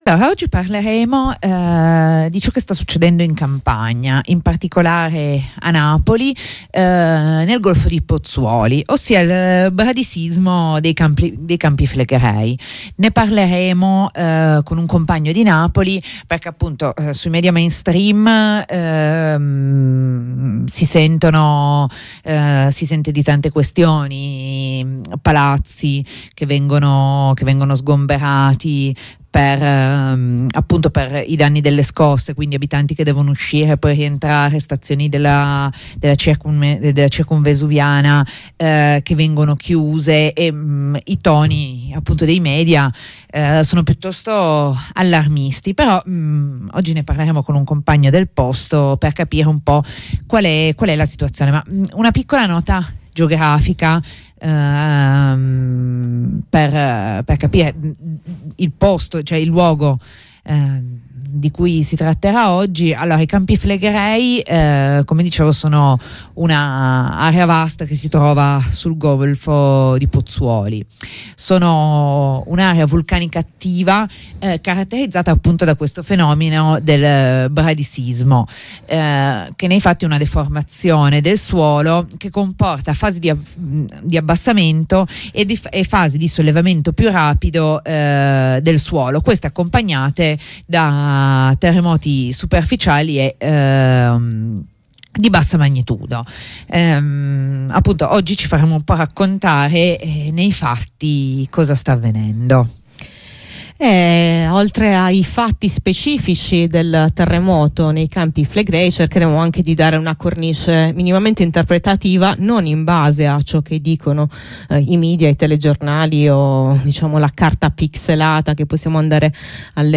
Insieme a un compagno che lì ci vive proviamo a capire qual è il sentire della popolazione locale, in maniera non filtrata dal sensazionalismo giornalistico.